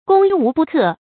發音讀音
成語簡拼 gwbk 成語注音 ㄍㄨㄙ ㄨˊ ㄅㄨˋ ㄎㄜˋ 成語拼音 gōng wú bù kè 發音讀音 常用程度 常用成語 感情色彩 中性成語 成語用法 兼語式；作謂語、定語；形容力量無比強大 成語結構 復雜式成語 產生年代 近代成語 近義詞 戰無不勝 反義詞 望風而逃 成語例子 中國人民解放軍 攻無不克 ，戰無不勝，所向披靡。